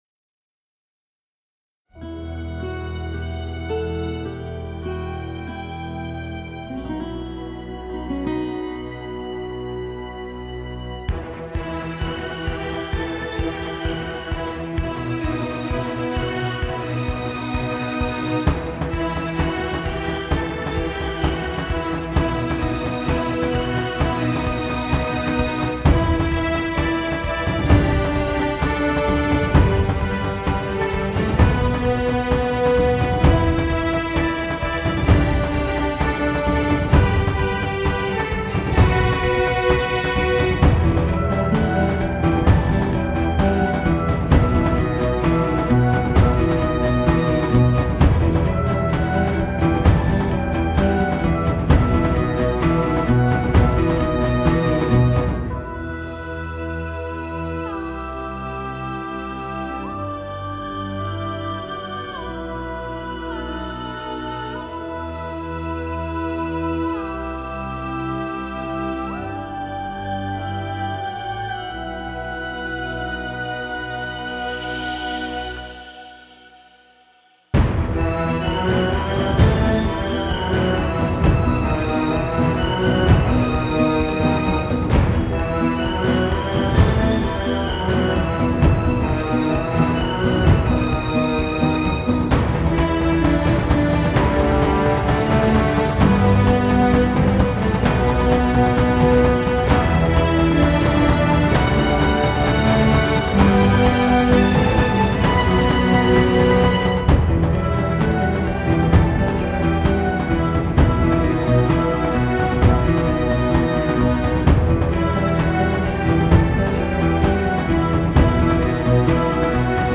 epic orchestral version